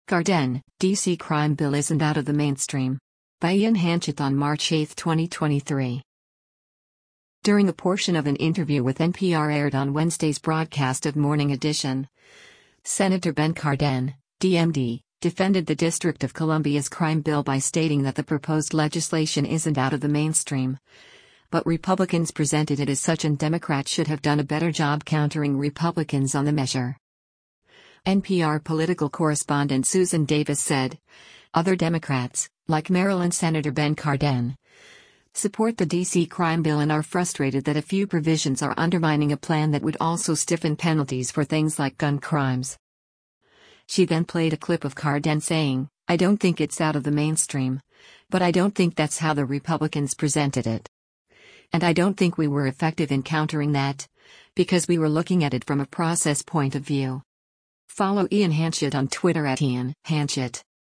During a portion of an interview with NPR aired on Wednesday’s broadcast of “Morning Edition,” Sen. Ben Cardin (D-MD) defended the District of Columbia’s crime bill by stating that the proposed legislation isn’t out of the mainstream, but Republicans presented it as such and Democrats should have done a better job countering Republicans on the measure.